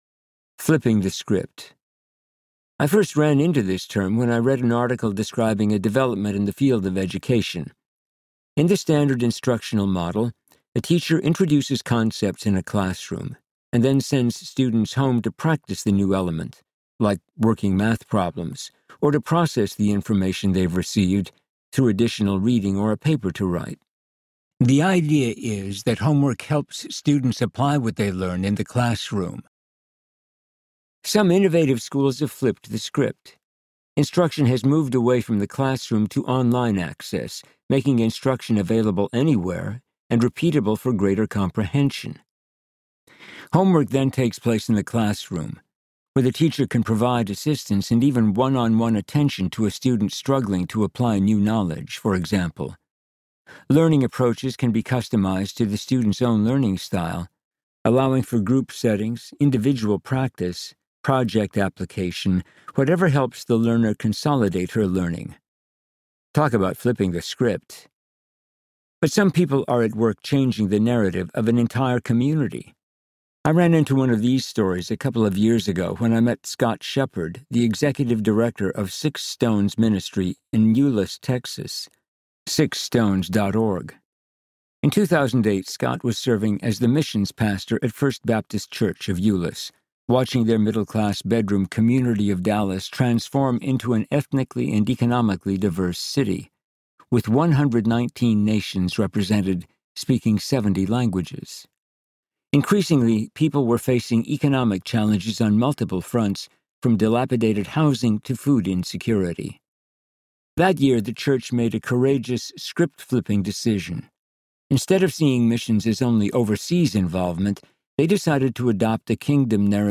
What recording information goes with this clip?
4.67 Hrs. – Unabridged